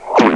00266_Sound_popinverse.mp3